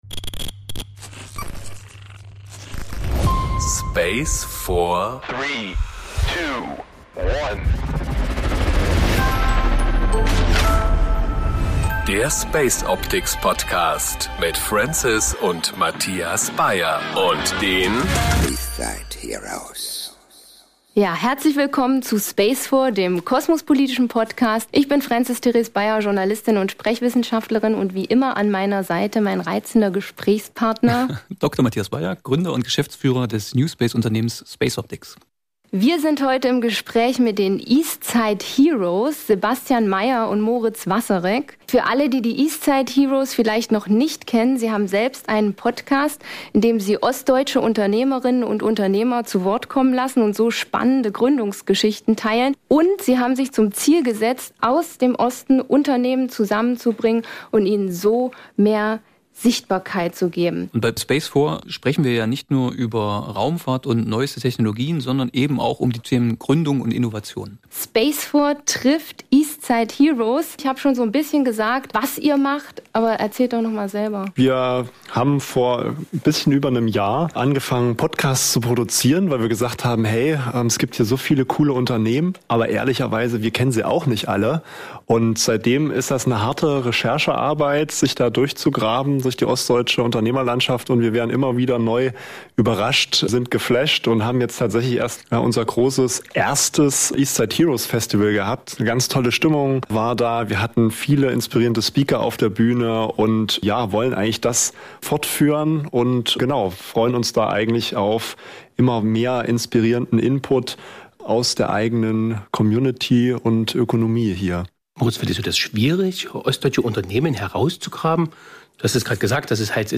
Gemeinsam sprechen wir über Unternehmertum im Osten, den Mut, Neues zu wagen, und die Bedeutung von Hightech aus Thüringen für die Raumfahrt. Ein Gespräch über Haltung, Herkunft und die Kraft, aus dem Osten heraus Zukunft zu gestalten.